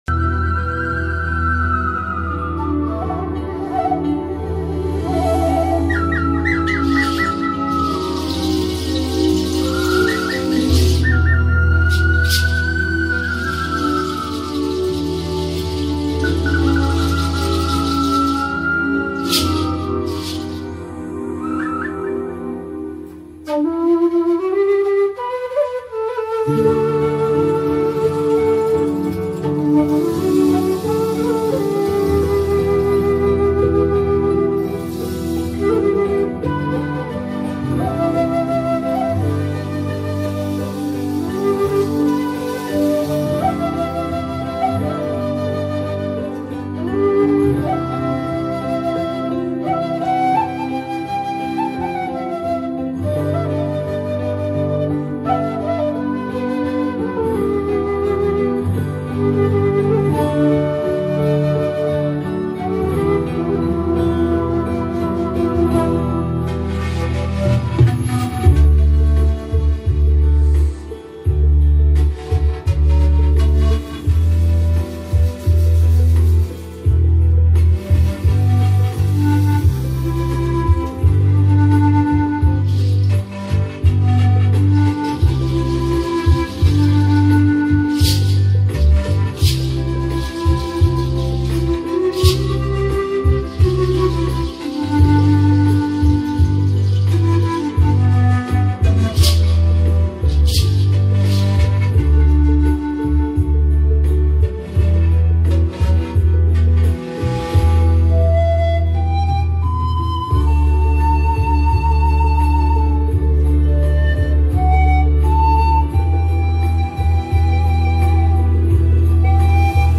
Panflute and quenacho